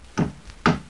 Hammer Sound Effect
Download a high-quality hammer sound effect.
hammer.mp3